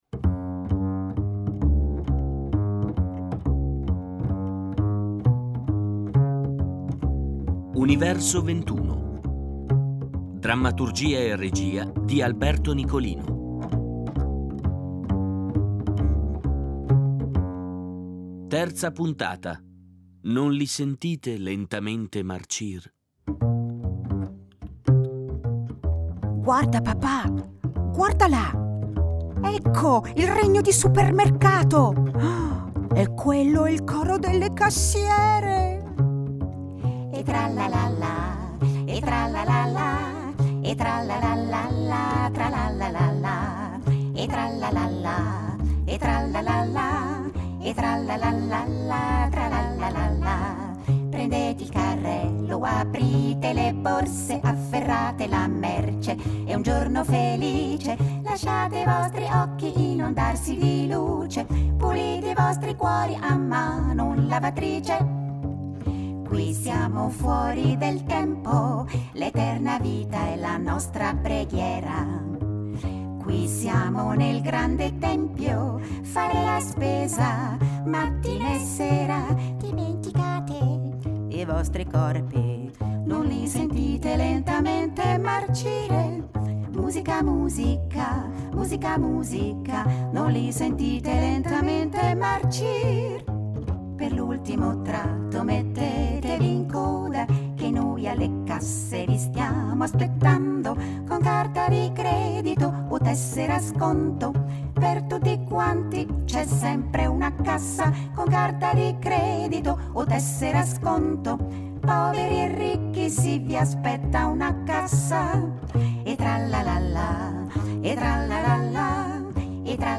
Radiodramma (puntata3) | Fiaba e Narrazioni